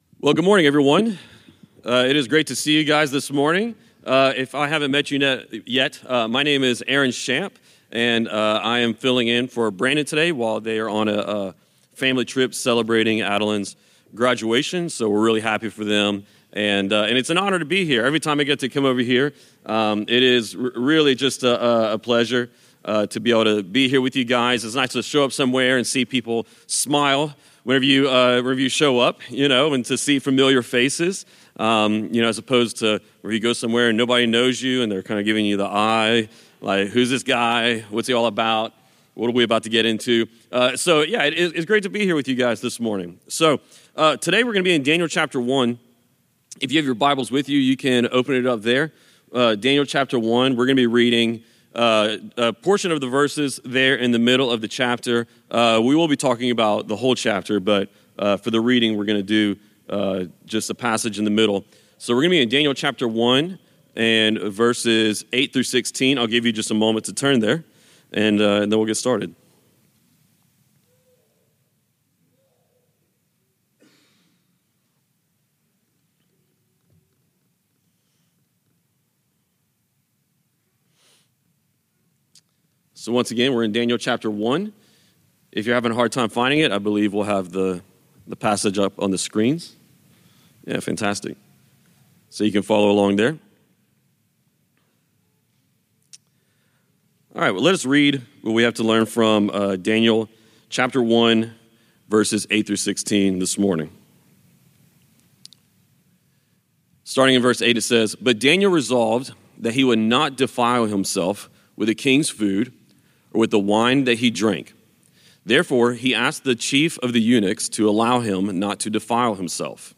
This is a part of our sermons.